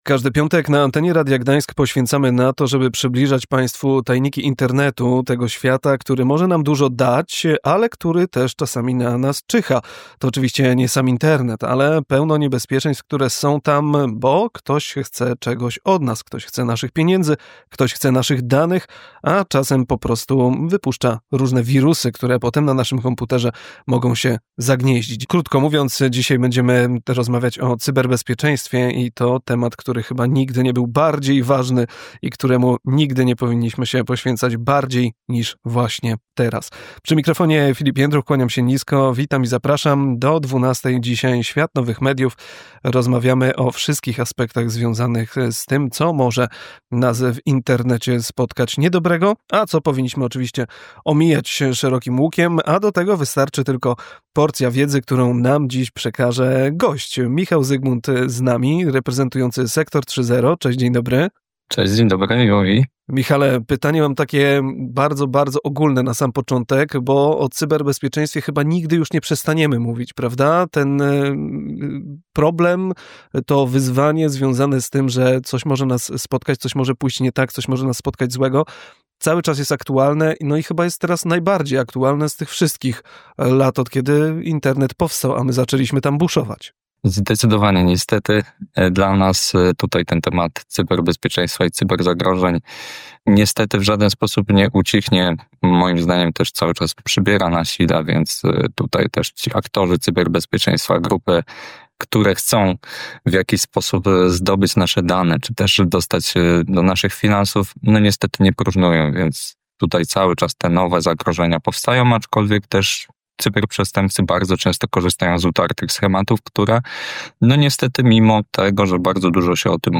W audycji „Świat Nowych Mediów” rozmawialiśmy o cyberbezpieczeństwie, zagrożeniach w sieci i metodach ochrony przed oszustami internetowymi.